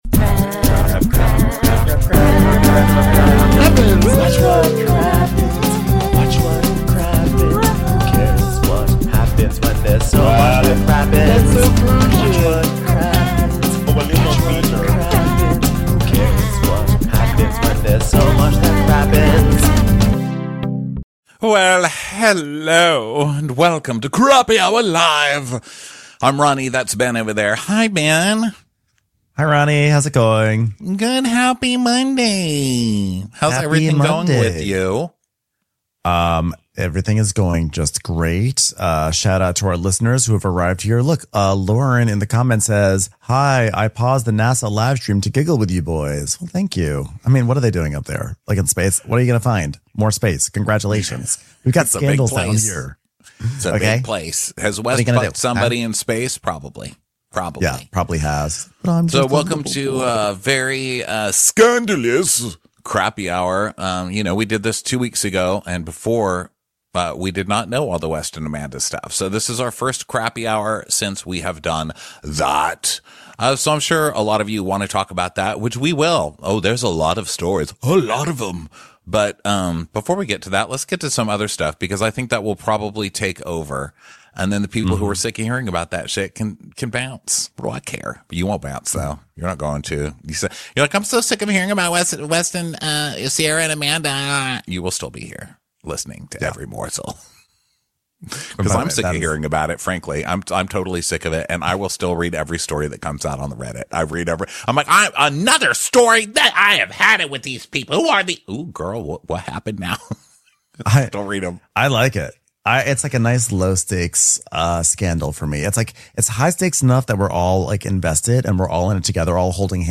This week on Crappy Hour Live, we talk about Kim Zolciak’s custody struggles, Jen Shah’s post prison sit down, and the latest Summer House scandal dramz.